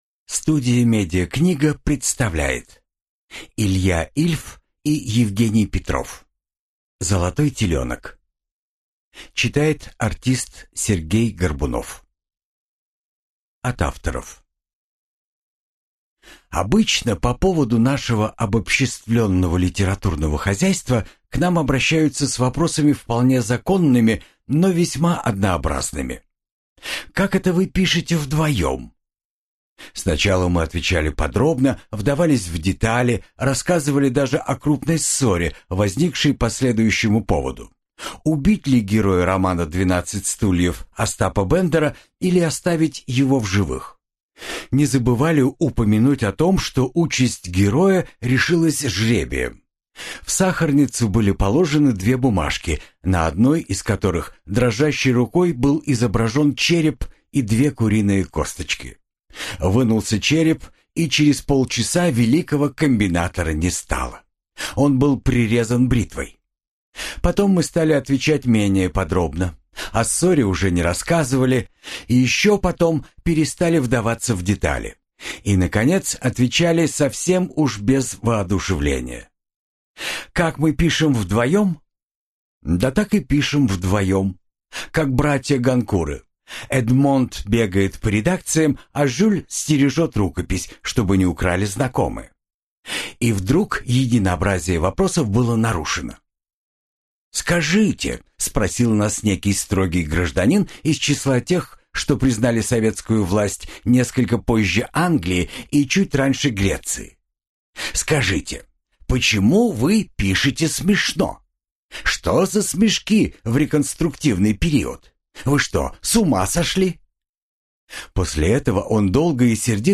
Аудиокнига Золотой теленок | Библиотека аудиокниг